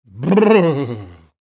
Brrr Sound Effect – Cold Shivering Human Voice
This high-quality recording captures a clear human voice delivering a sharp “Brrr” sound to imitate shivering from cold. The dry, one-second sample is easy to drop into winter-themed scenes, memes, or comedic projects. An authentic vocal effect that instantly sells freezing temperatures in a professional way.
Brrr-sound-effect.mp3